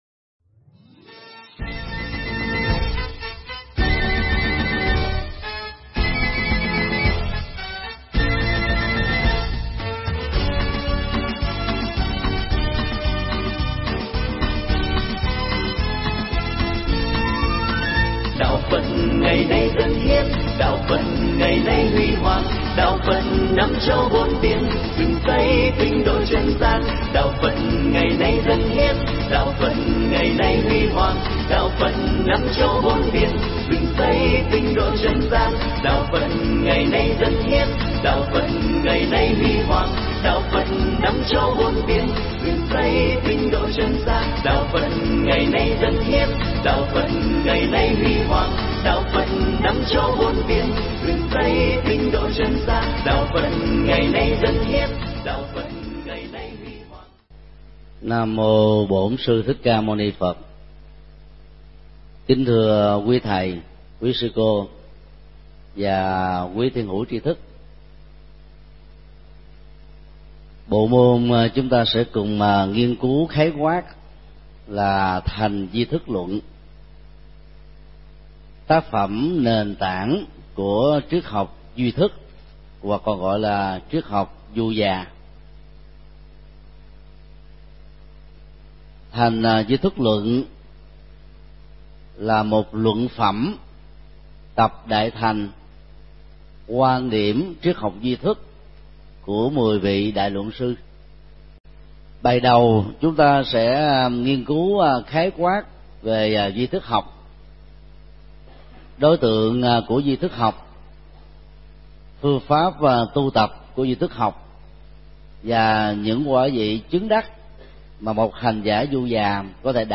Mp3 Thuyết Pháp Tâm Thức Học Phật giáo 01 – Giới Thiệu Bao Quát – Thầy Thích Nhật Từ giảng tại Học Viện Phật Giáo Việt Nam, tp.hcm ngày 9 tháng 11 năm 2012